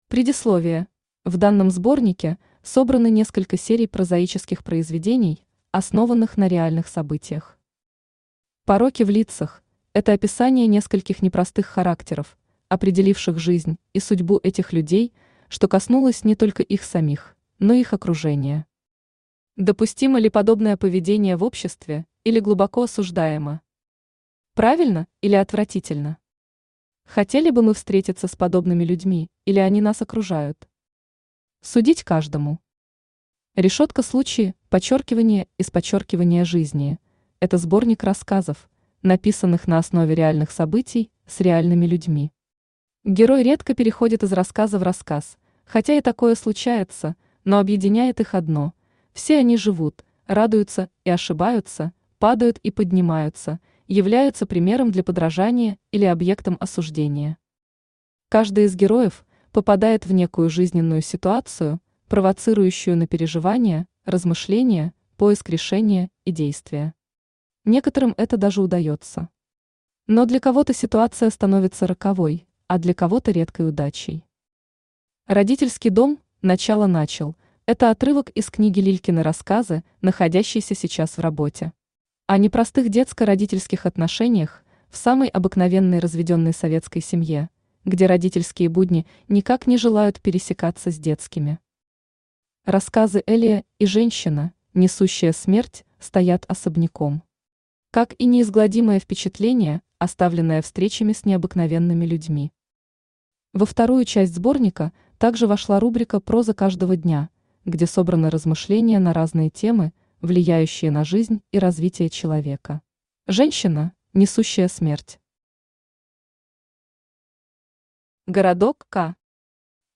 Аудиокнига Женщина, несущая смерть | Библиотека аудиокниг
Aудиокнига Женщина, несущая смерть Автор Юлия Алексеевна Титова Читает аудиокнигу Авточтец ЛитРес.